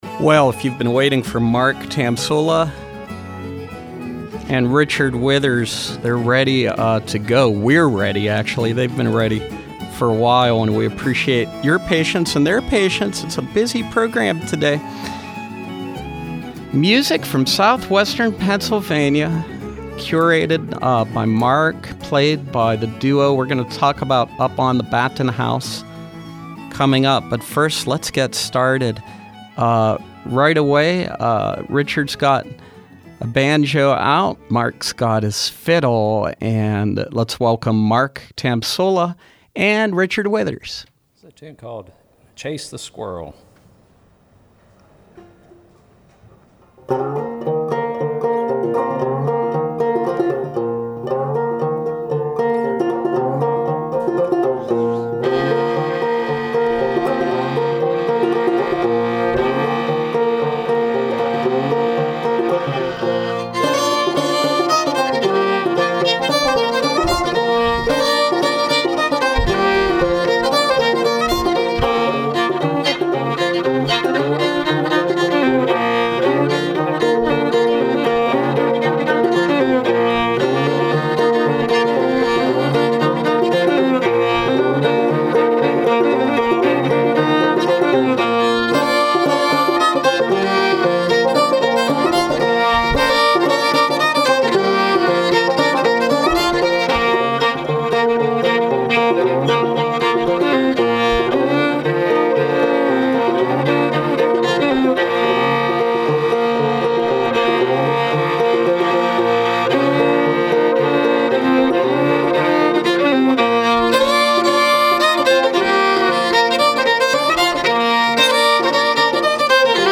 Fiddle
multi-instrumentalist
old time, southwestern Pennsylvania music